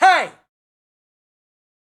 Звук хей мужской и мощный